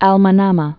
(ăl mə-nămə, äl mə-nämə)